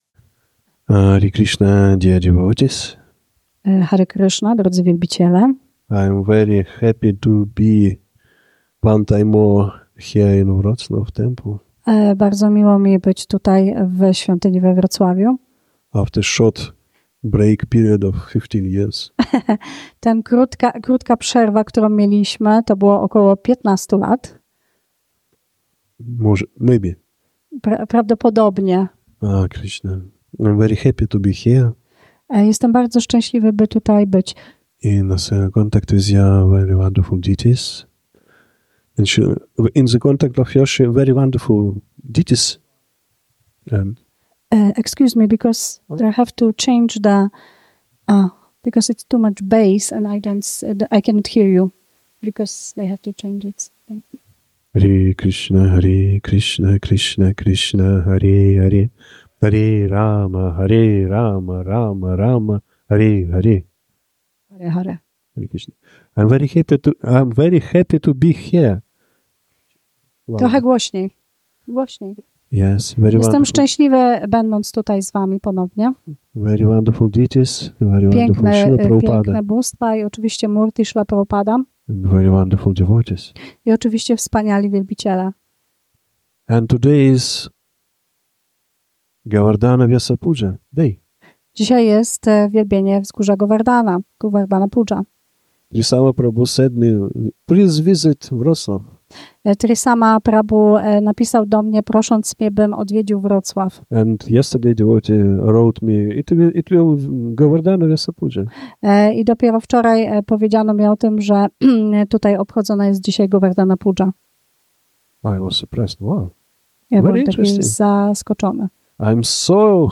Wykład z okazji Govardhana Pujy wygłoszony 26 października 2025 roku.